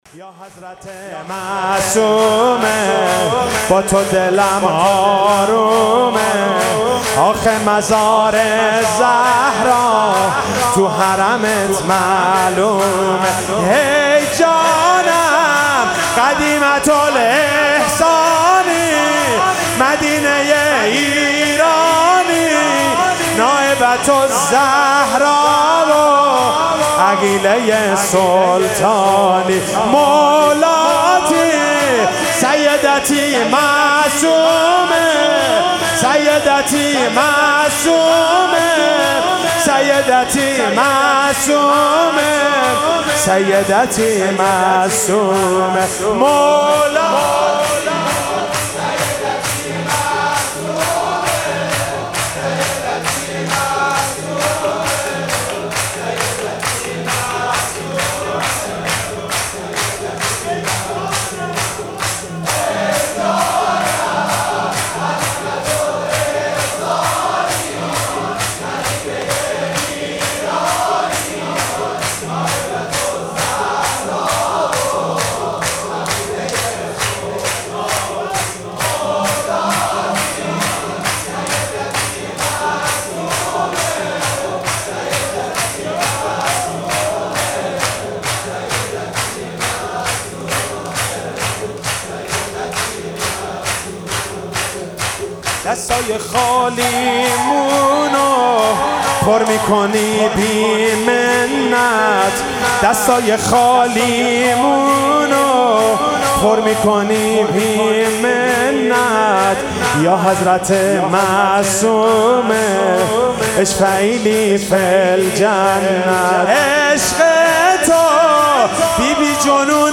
ولادت حضرت معصومه سلام الله علیها